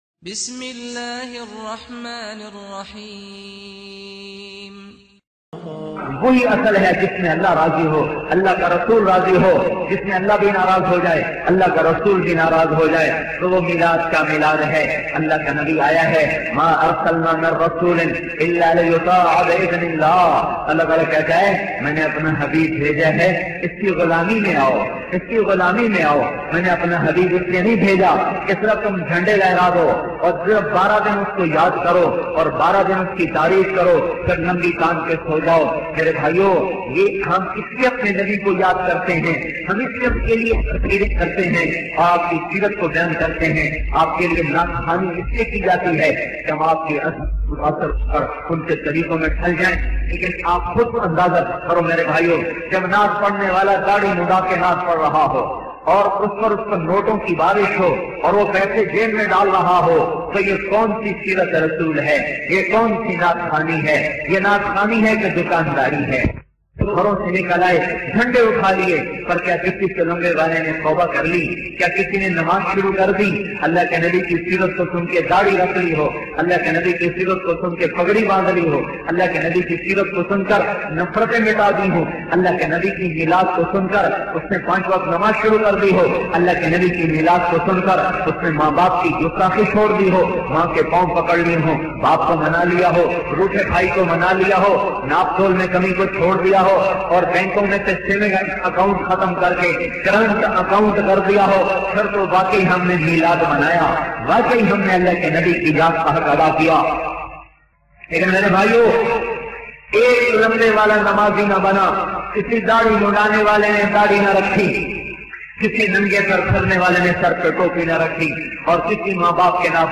Importance Of Milad bayan by tariq jameel.